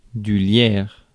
Ääntäminen
Ääntäminen France: IPA: /ljɛʁ/ Haettu sana löytyi näillä lähdekielillä: ranska Käännös Ääninäyte Substantiivit 1. ivy US Suku: m .